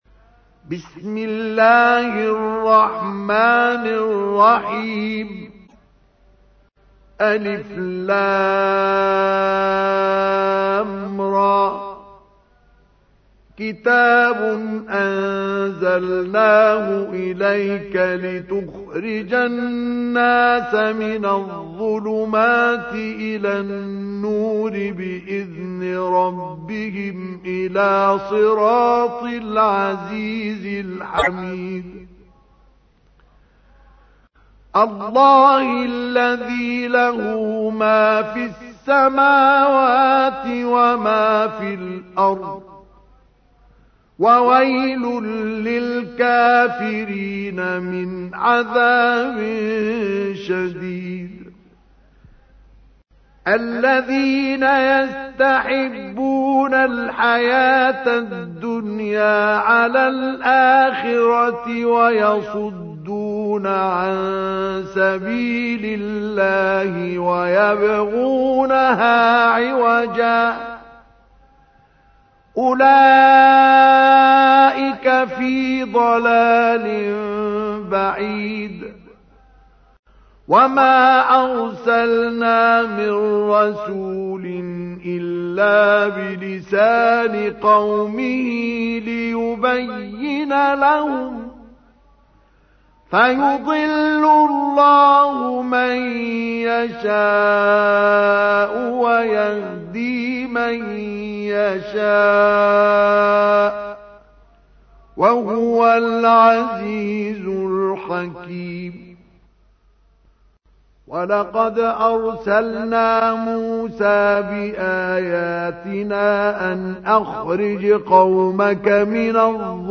تحميل : 14. سورة إبراهيم / القارئ مصطفى اسماعيل / القرآن الكريم / موقع يا حسين